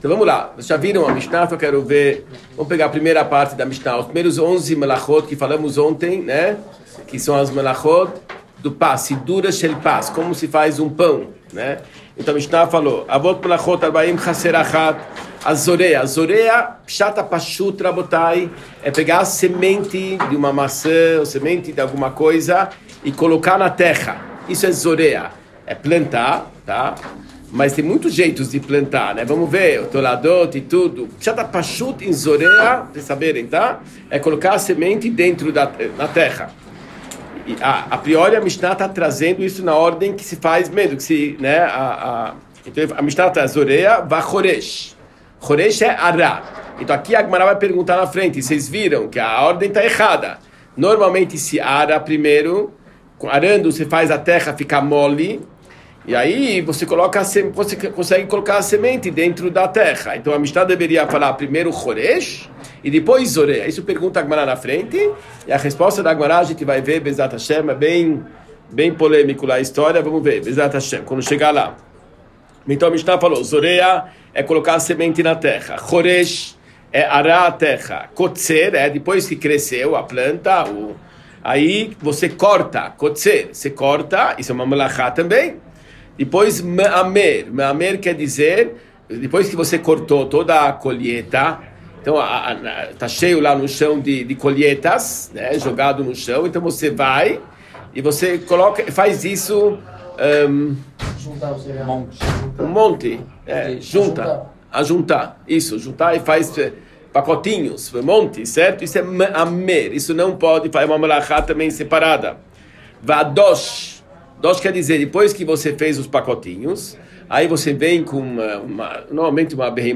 Shiur 39 Melachot – Parte 2